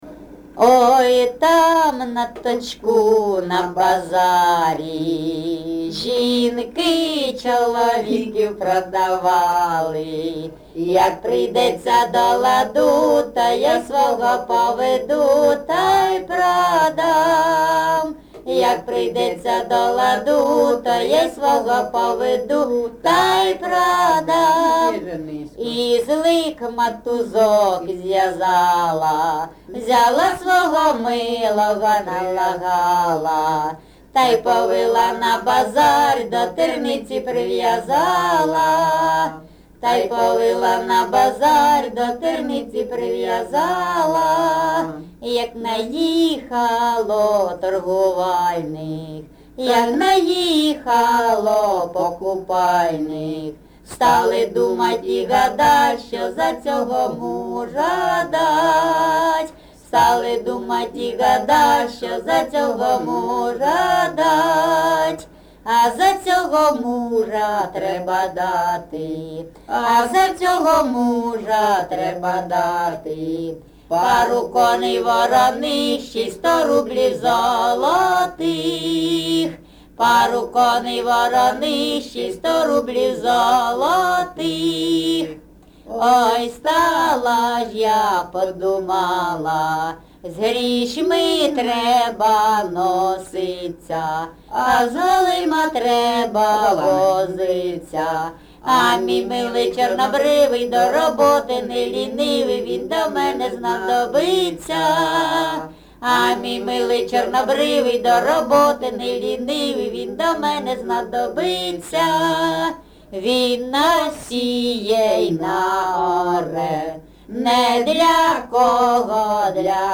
ЖанрЖартівливі
Місце записум. Сіверськ, Артемівський (Бахмутський) район, Донецька обл., Україна, Слобожанщина